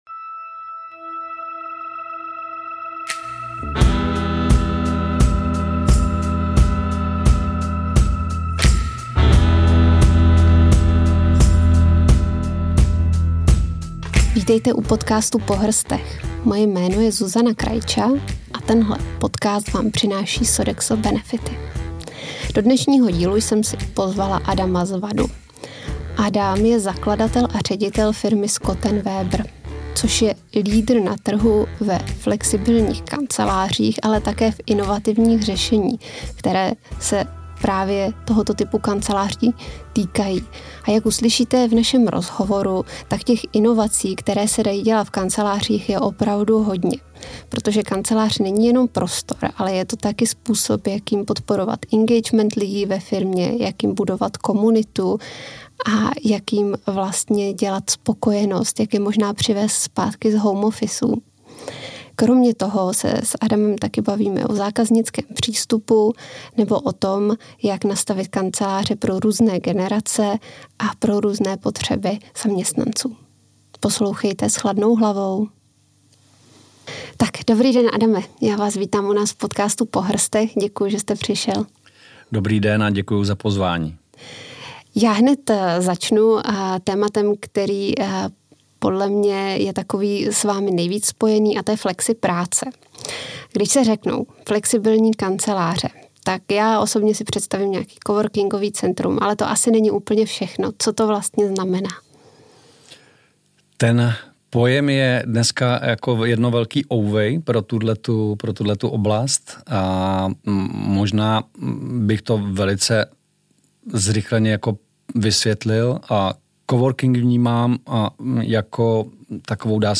V rozhovoru si povídáme o tom, jak mohou kanceláře podporovat engagement lidí a budovat komunity, jaký vliv mají na návrat zaměstnanců z home office nebo jak je možné nastavit kanceláře pro různé generace a jejich potřeby.